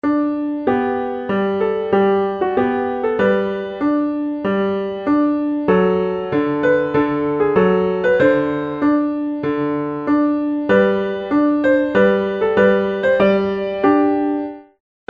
Partitura para piano.